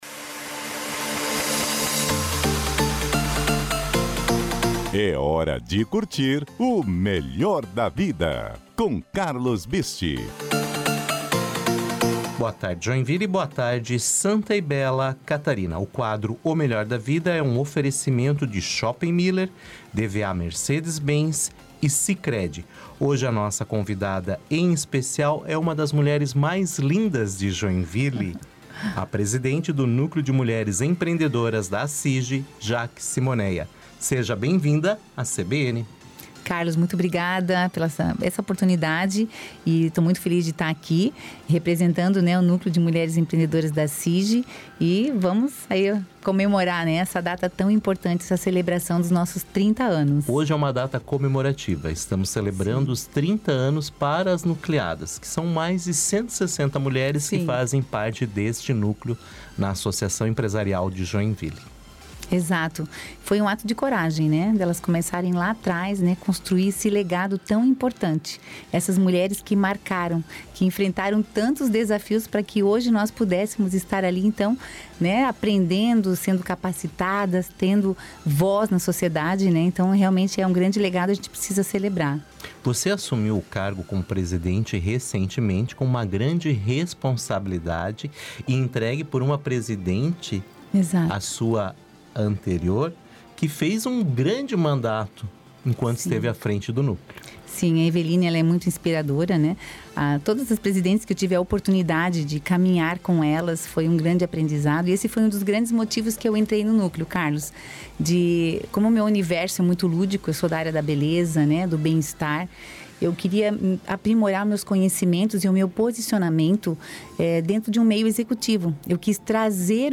CLIQUE AQUI para ouvir íntegra da entrevista da presidente do Núcleo